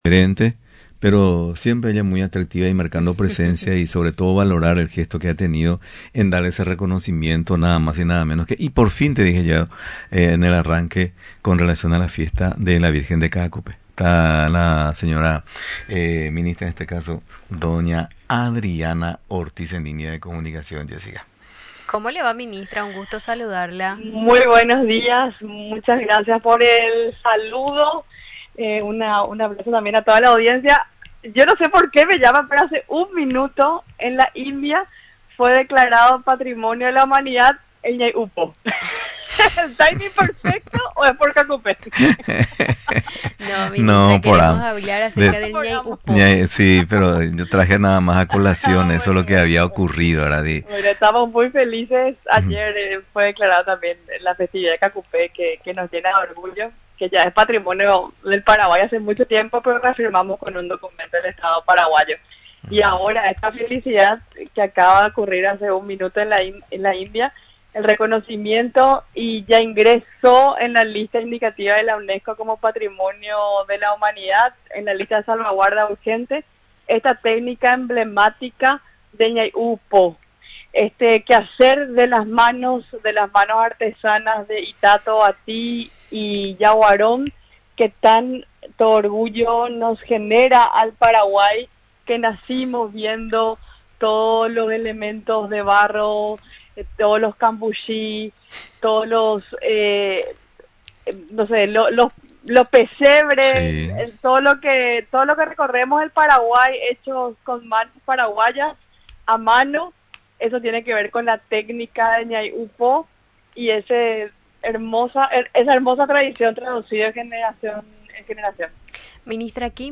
La Ministra de Cultura, Adriana Ortiz, en comunicación con Radio Nacional celebró el reciente reconocimiento del Ñai’ũpo como Patrimonio Cultural Inmaterial de la Humanidad en la lista de salvaguardia urgente de la UNESCO, una decisión tomada en la India.